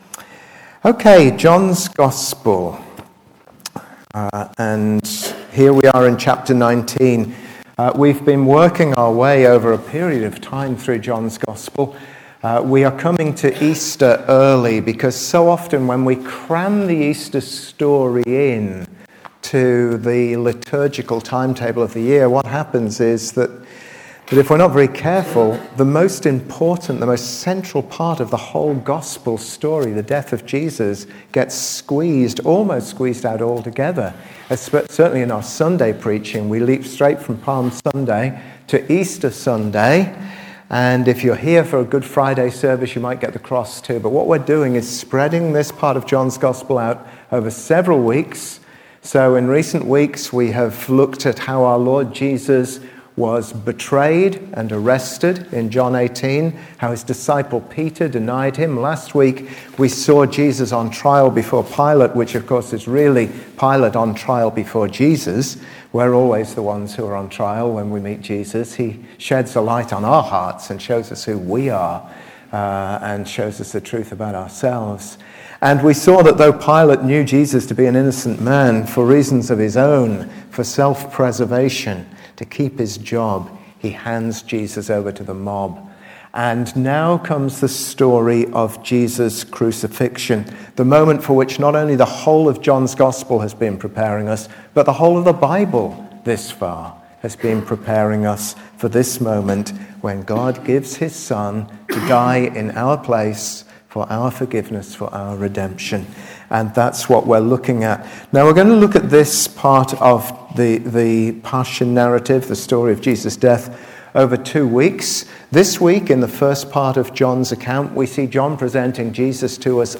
John Passage: John 19:16-27 Service Type: Sunday 9:15 Bible Text